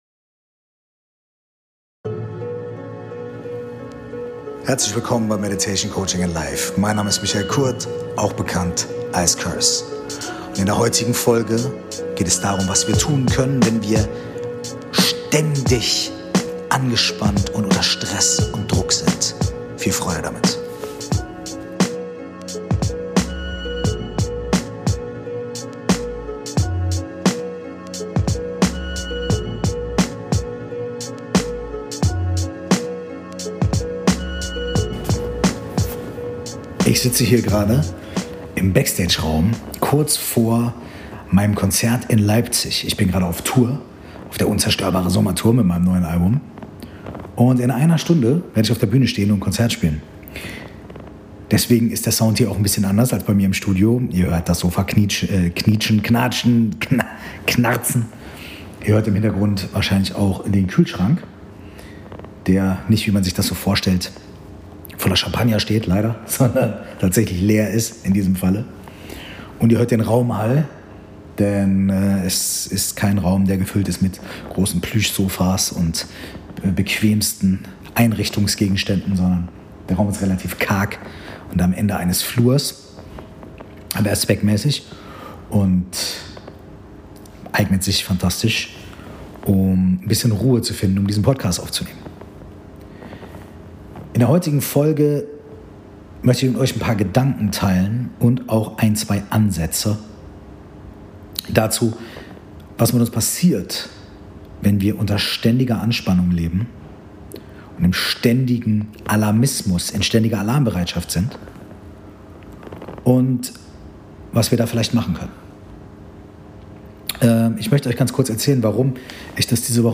1 SLEEP: Drifting Through the Sleepy Skies Meditation (Female Voice) 27:21